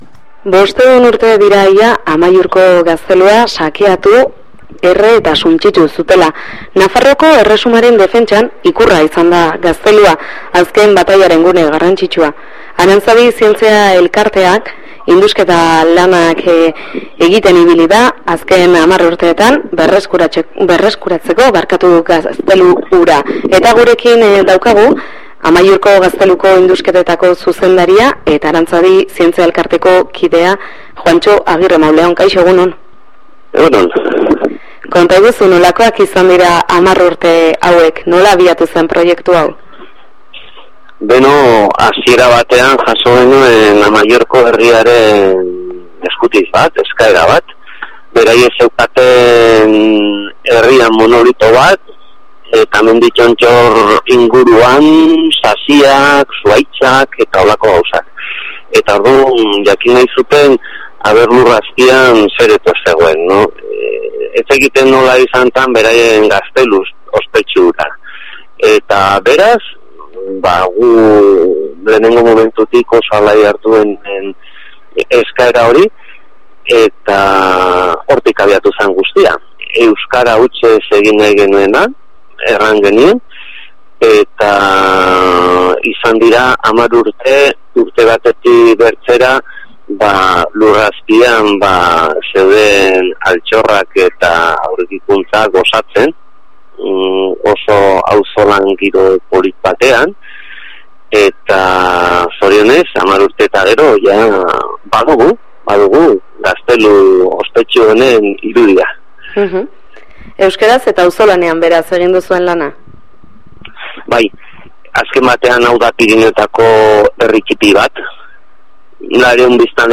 Klikatu hemen elkarrizketa jaisteko Partekatu Click to share on Facebook (Opens in new window) Click to share on Twitter (Opens in new window) Click to email a link to a friend (Opens in new window) Related